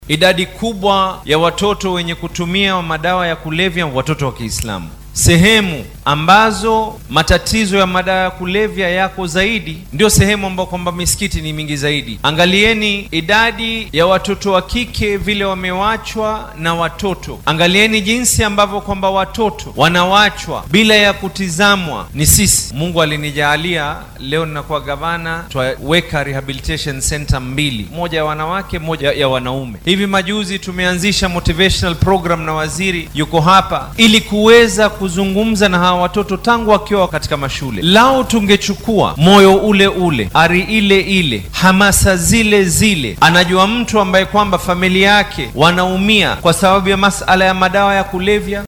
DHAGEYSO:Barasaabka Mombasa oo ka hadlay isticmaalka maandooriyaha